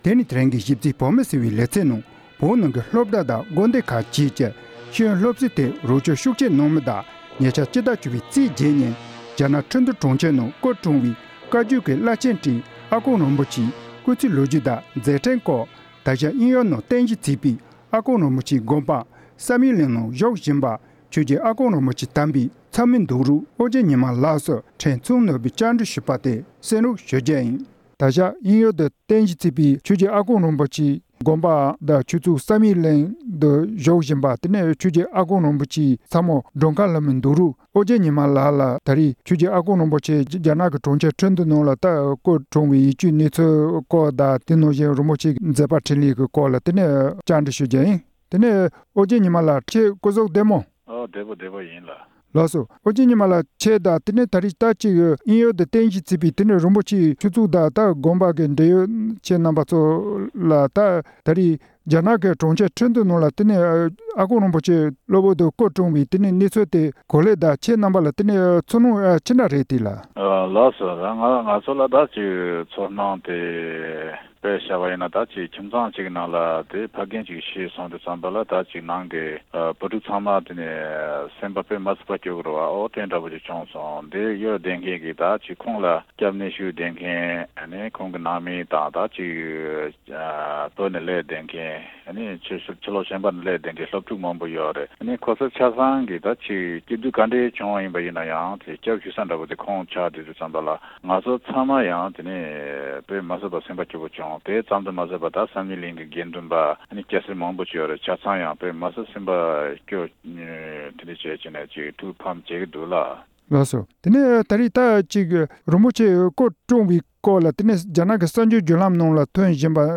གནས་འདྲི་ཞུས་པ་དེ་གསན་རོགས་ཞུ།།